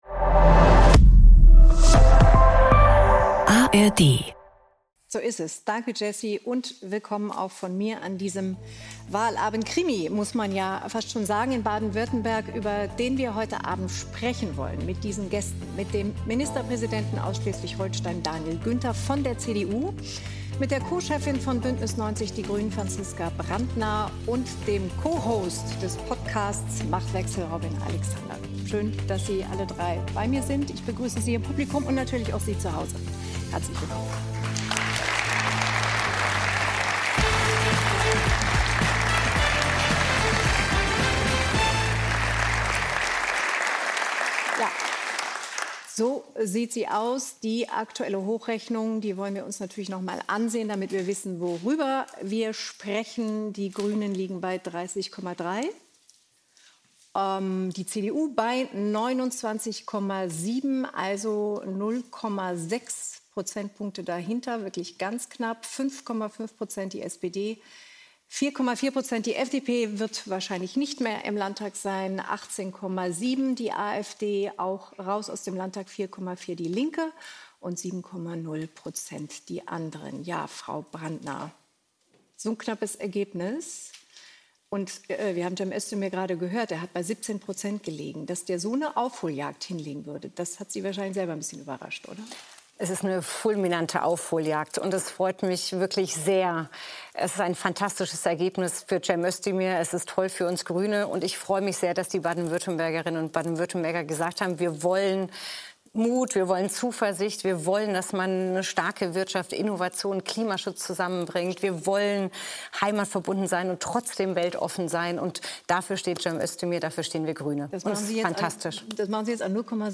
Caren Miosga diskutiert mit ihren Gästen, ob Özdemirs Abgrenzung zur eigenen Partei die richtige Strategie war, ob die öffentliche Kontroverse über Hagels umstrittene Aussage über Schülerinnen im Jahr 2018 noch Einfluss auf das Wahlergebnis hatte, ob sich die aktuellen Umfragezahlen für die AfD am Wahlabend bestätigen und welche Lehren sich aus dem Wahlergebnis für die Bundespolitik ziehen lassen?